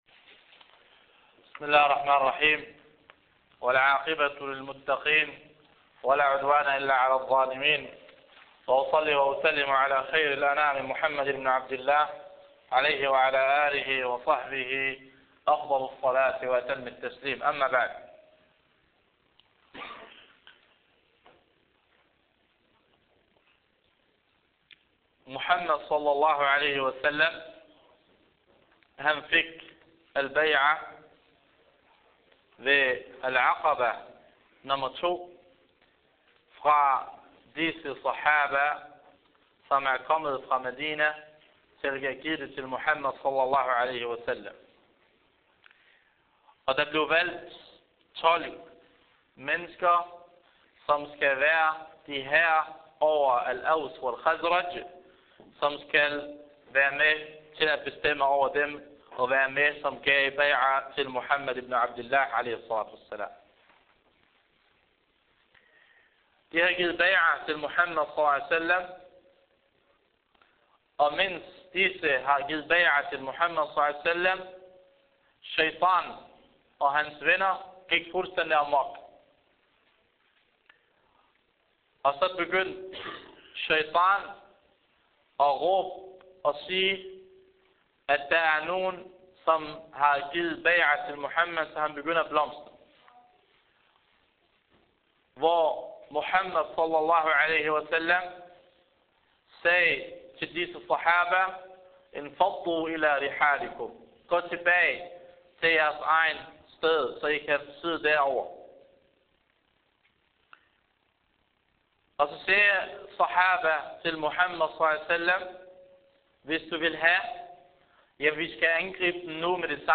Århus konference December 2010
Dars Muhammads (saws) Seerah E.mp3